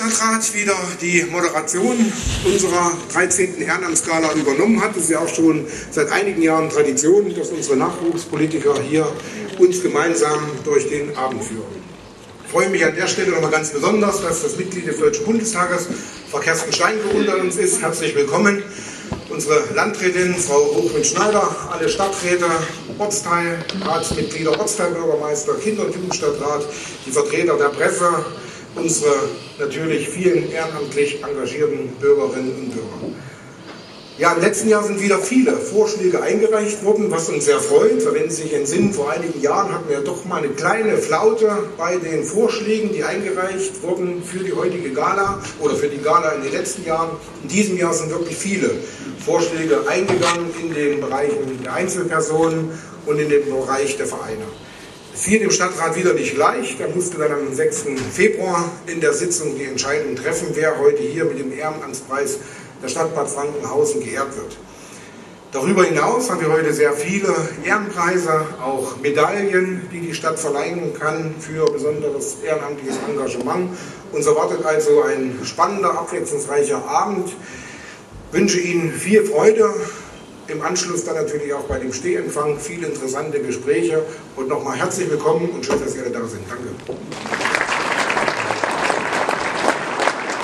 Bereits zum 14. Mal fand gestern am Abend in Bad Frankenhausen die Ehrenamts-Gala der Stadt Bad Frankenhausen statt.
Dann ergriff der Hausherr, Bürgermeister Matthias Strejc (SPD) das Wort und begrüßte die Gäste im Saal.
Begrüßung